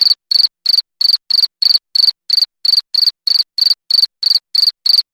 Cricket.mp3